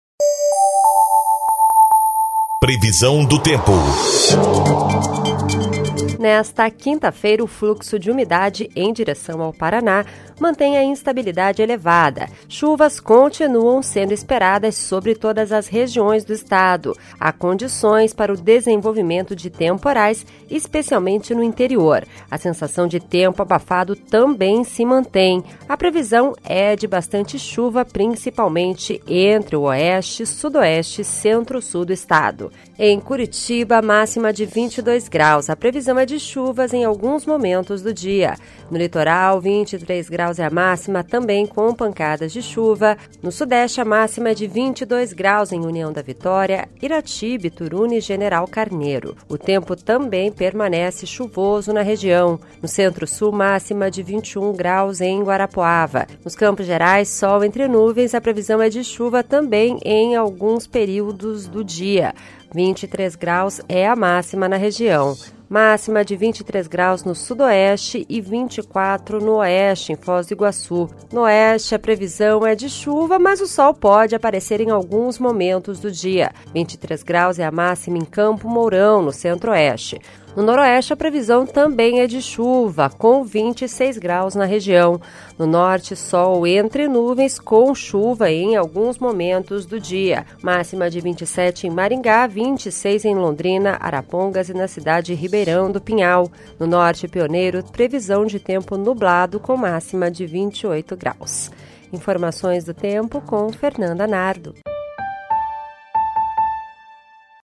Previsão do Tempo (13/10)